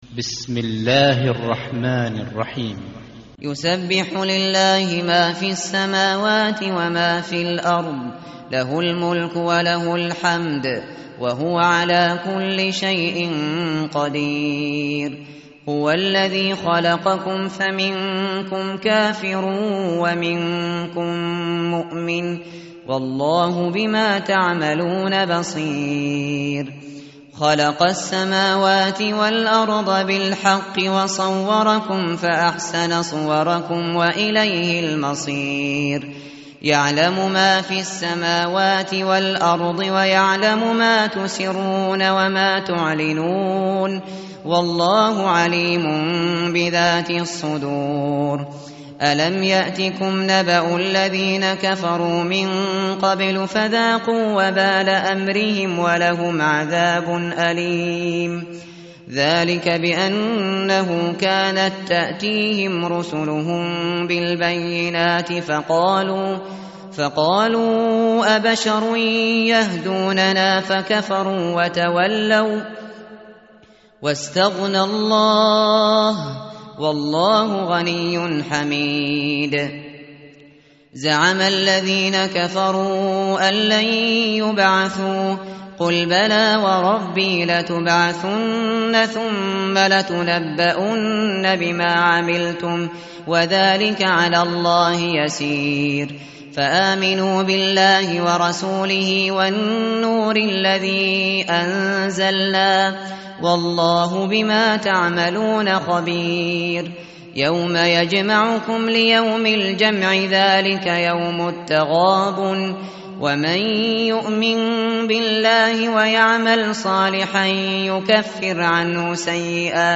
tartil_shateri_page_556.mp3